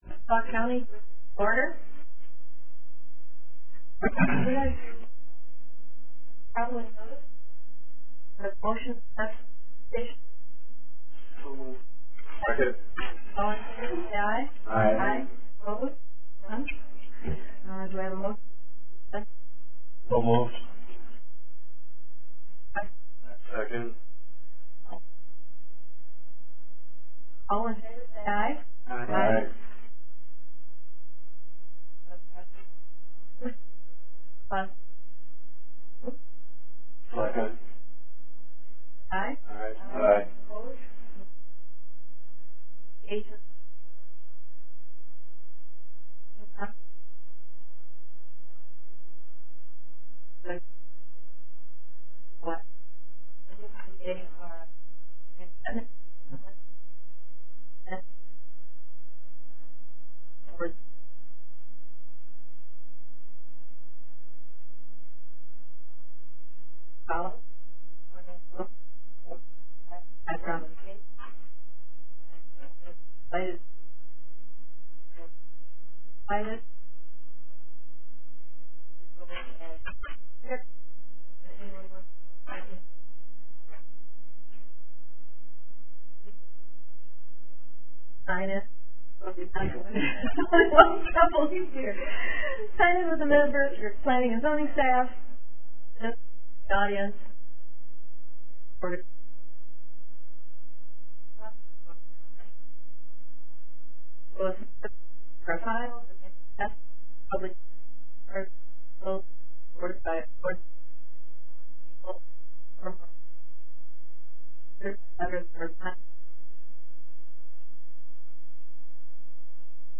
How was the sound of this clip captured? Meeting Information Agenda Minutes Agenda (25 KB) NOTICE OF MEETING SAUK COUNTY BOARD OF ADJUSTMENT DATE: January 26, 2012 TIME: 9:00 a.m. PLACE: Board Room, Sauk County West Square Building 505 Broadway Baraboo, WI. 53913 ORDER OF BUSINESS 1.